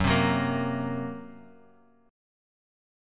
F#Mb5 chord